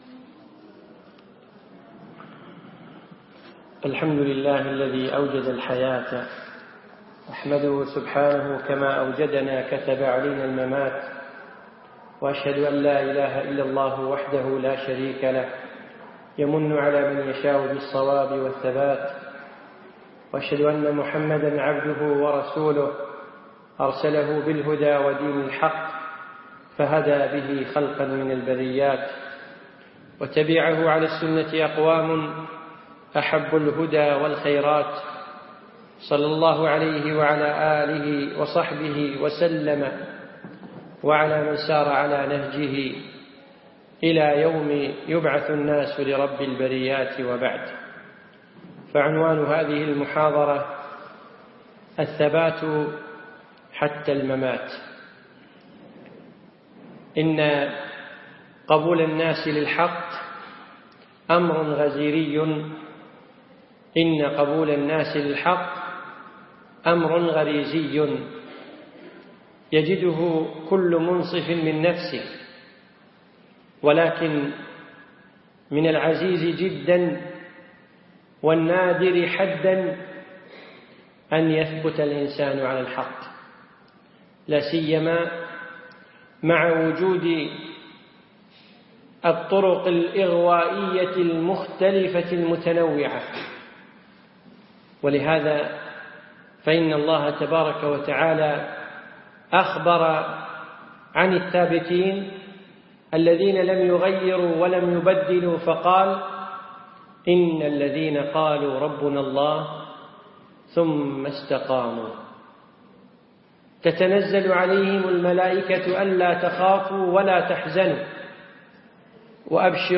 يوم الثلاثاء العصر في مركز القرين نساء مسائي